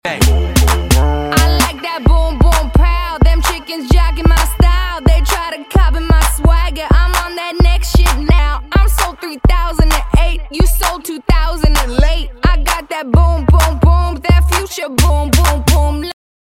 • Hip Hop